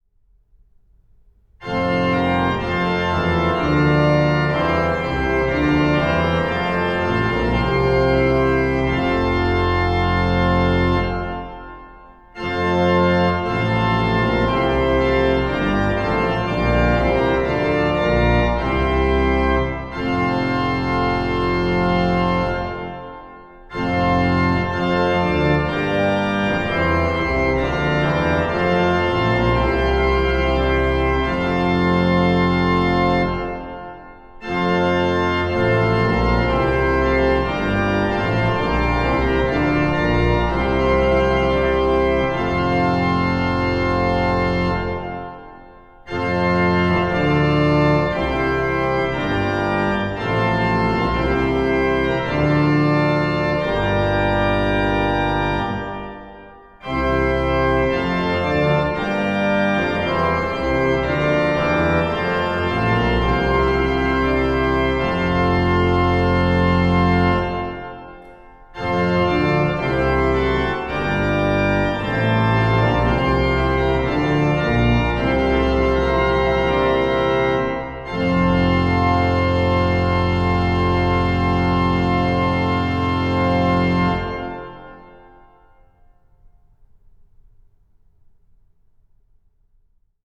Auswahl einiger Klangbeispiele von Instrumenten unserer Werkstatt aus verschiedenen CD-Produktionen und Aufnahmen (Hörproben im Format MP3).
Garbsen
ChoralAlleinGottinderHoehseiEhr.mp3